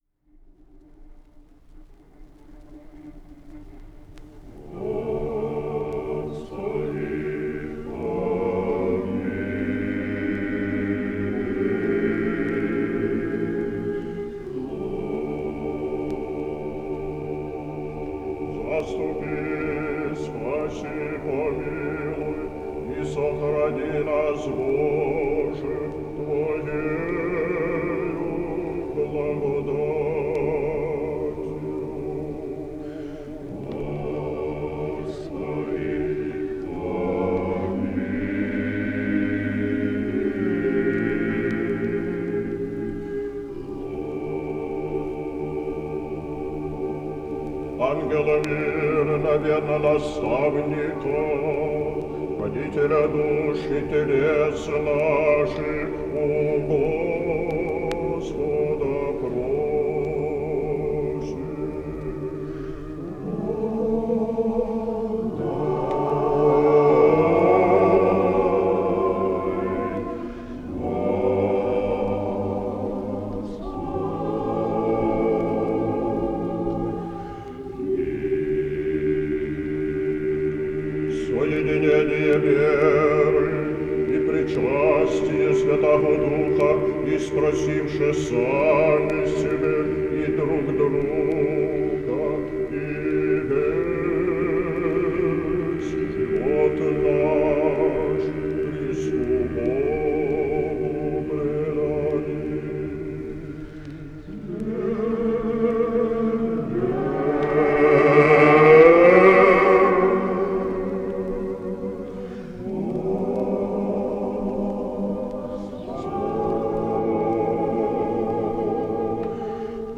The recording is that of older singers, who’s voices are not as fresh or supple as they once were